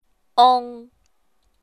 舒声韵的示范发音为阴平调，入声韵则为阴入调。
ong.mp3